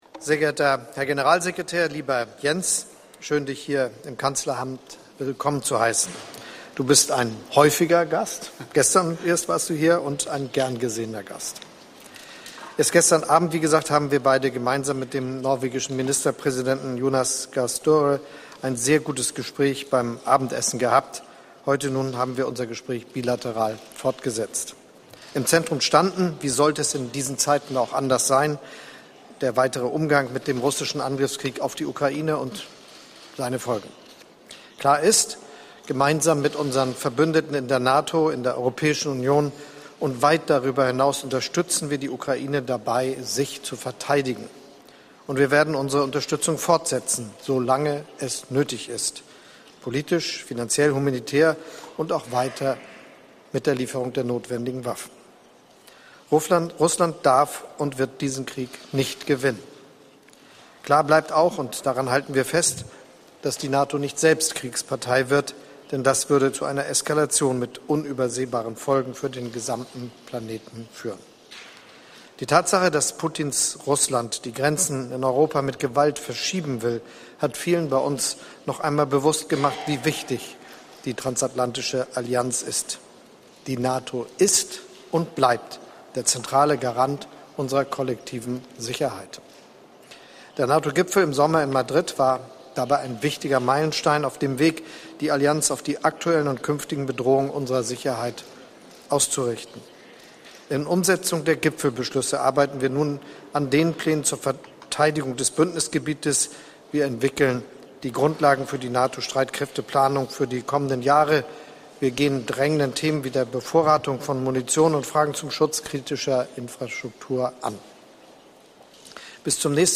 Joint press conference by NATO Secretary General Jens Stoltenberg and the Chancellor of the Federal Republic of Germany, Olaf Scholz